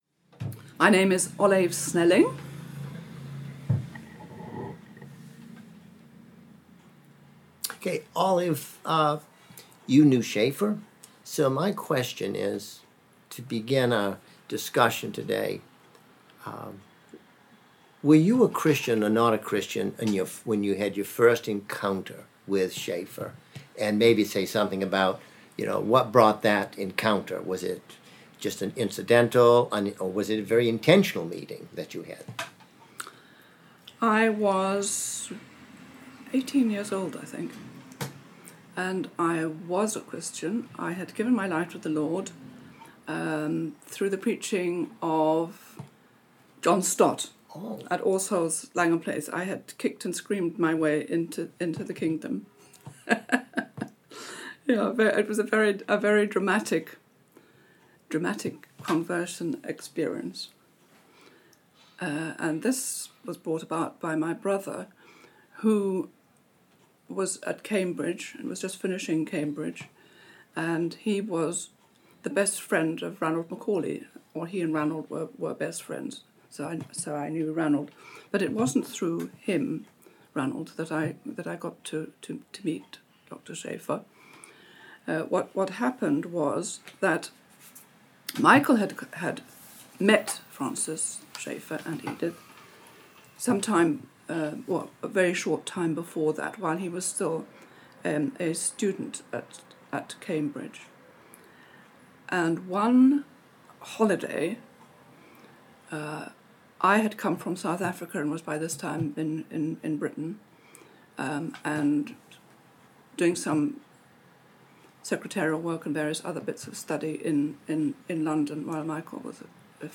Francis A. Schaeffer Oral History Interviews collection